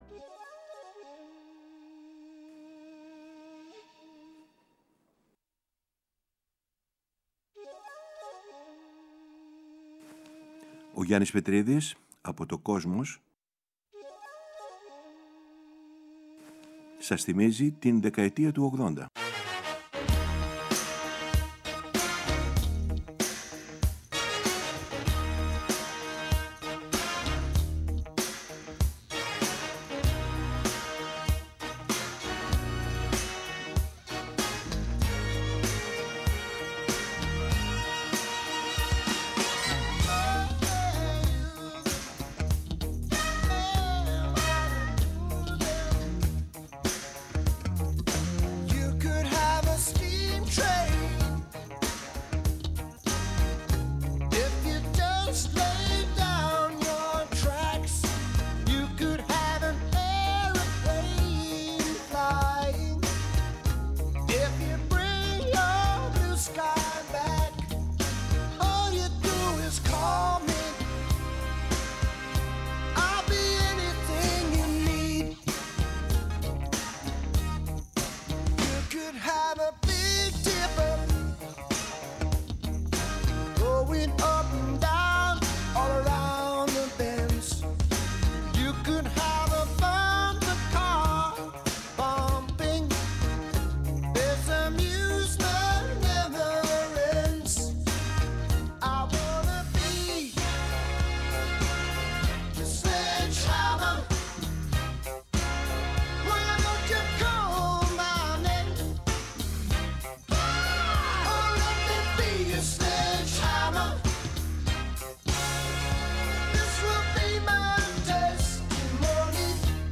Ακολουθούν μία ανασκόπηση του grunge, του τελευταίου σπουδαίου μαζικού κινήματος του ροκ, καθώς και αφιερώματα στο post punk, το trip hop, τη house, την electronica, τη χορευτική μουσική και άλλα καθοριστικά μουσικά είδη της συγκεκριμένης 20ετίας.